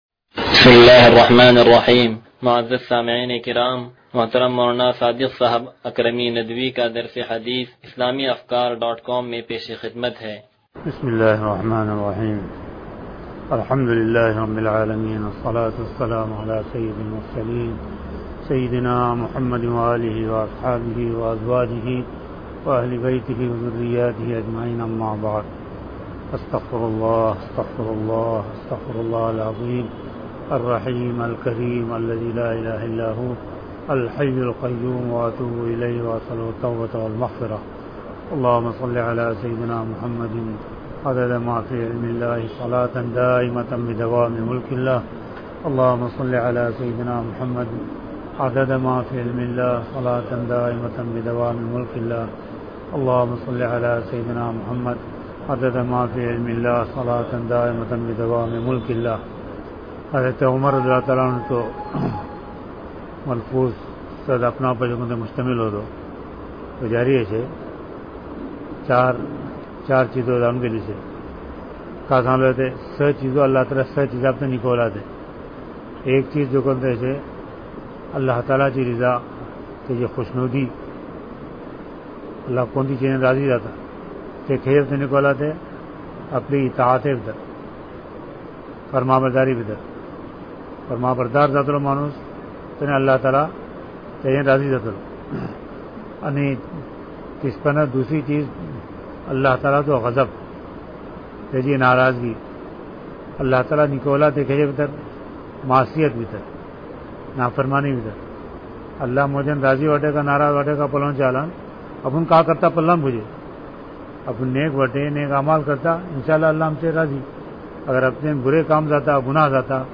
درس حدیث نمبر 0148
درس-حدیث-نمبر-0148.mp3